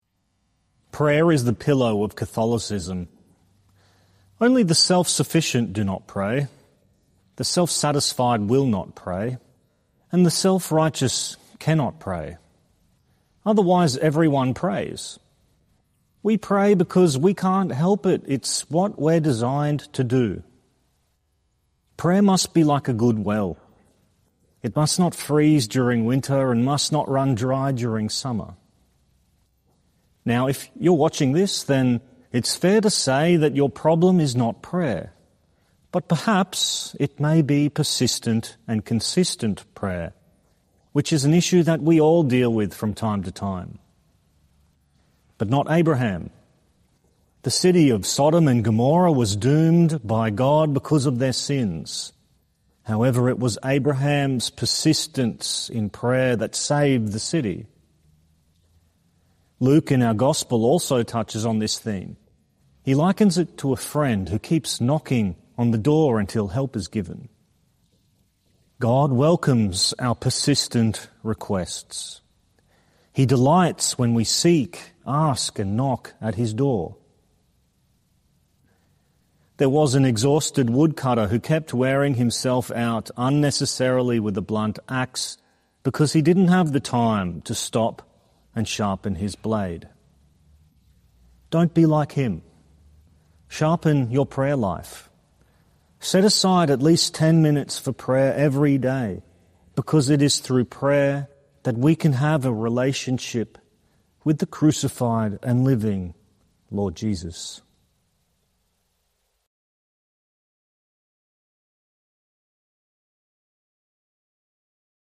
Archdiocese of Brisbane Seventeenth Sunday in Ordinary Time - Two-Minute Homily